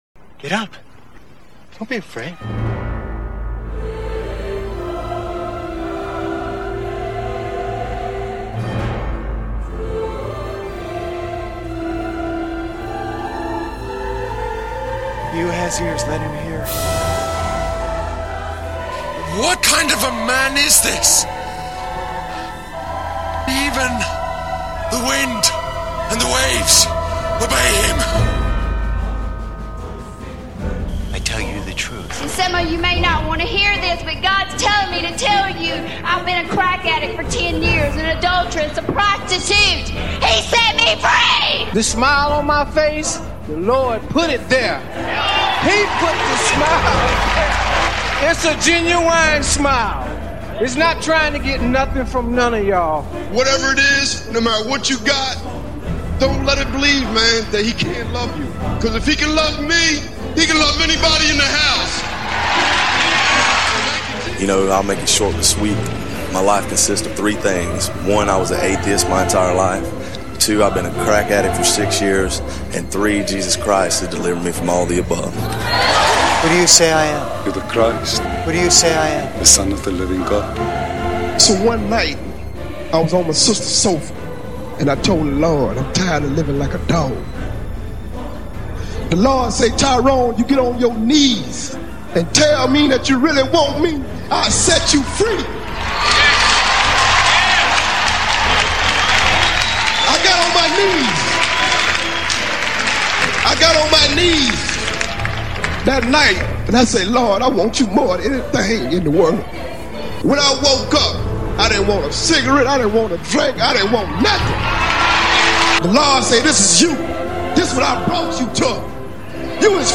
This sermon shares a powerful testimony of overcoming addiction through faith in Jesus Christ and emphasizes the importance of surrendering to God for true freedom and joy.